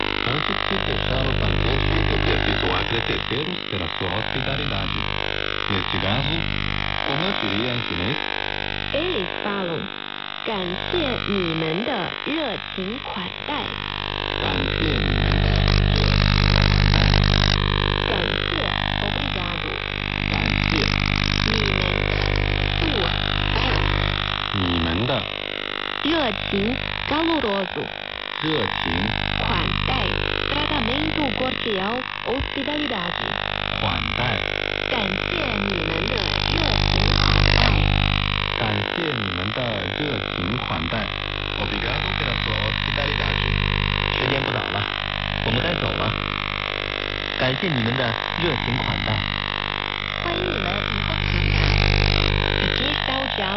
• ▲ ▼ Hallo Funkfreunde, Beim drehen über das SDR der Uni Twente ist mir ein signal aufgefallen das sich doch eher ungewöhnlich anhört. (6100 kHz AM) Könnte es sich da um ein AM Signal handeln das von einer anderen Stelle überlagert ("gejammt") wird?
Das ganze hört sich stark nach Magnetron/Mikrowelle an.
interference.mp3